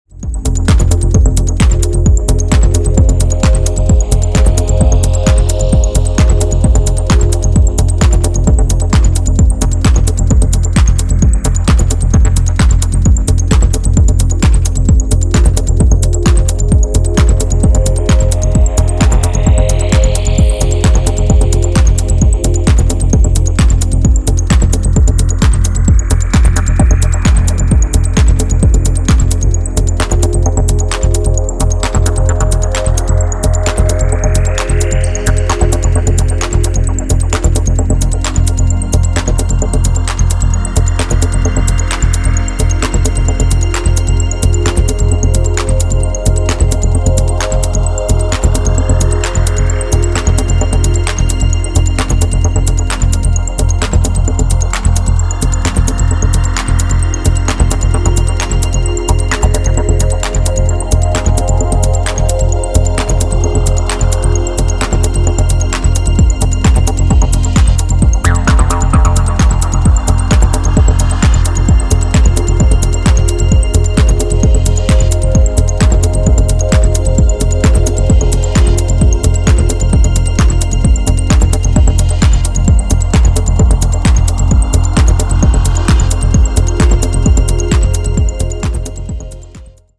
サイケデリックなレイヤーに没入するアシッド・ミニマル・テクノ
野外レイヴのクライマックスを想定していそうな、かなり気合の入った内容です。